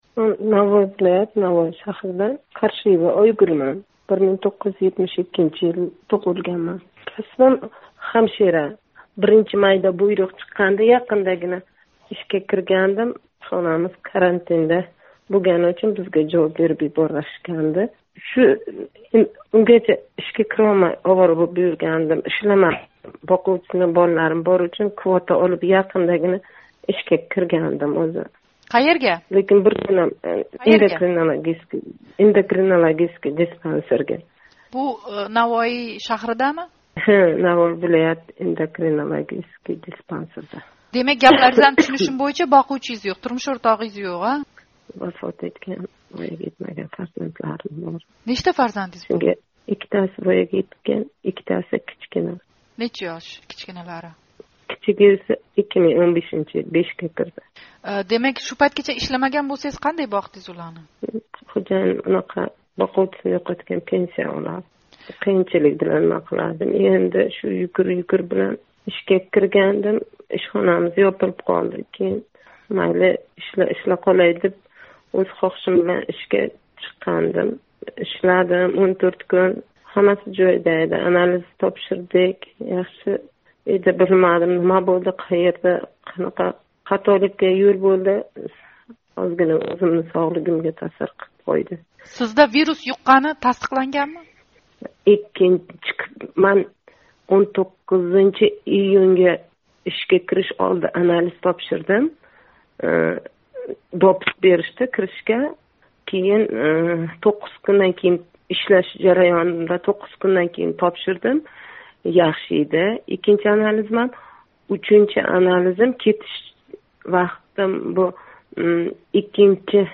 Уйида иситма билан қаровсиз қолиб кетган тошкентлик оиланинг қизи билан суҳбат